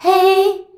HEY     G.wav